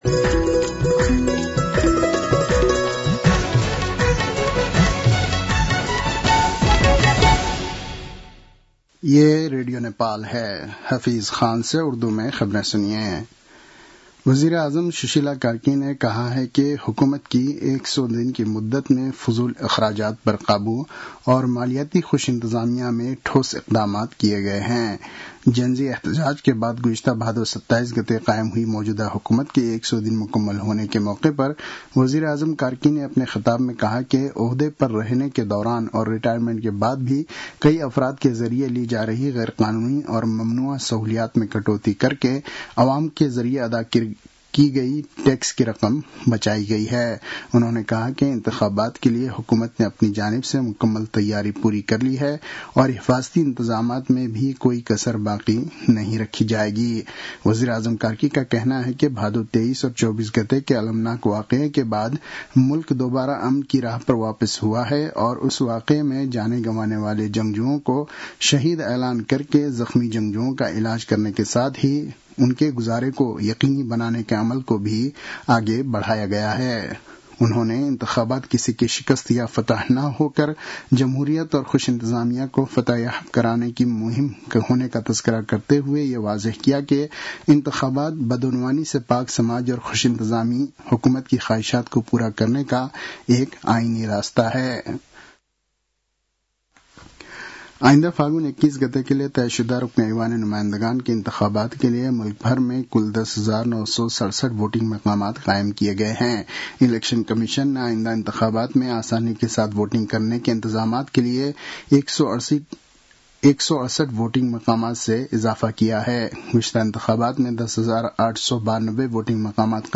उर्दु भाषामा समाचार : ५ पुष , २०८२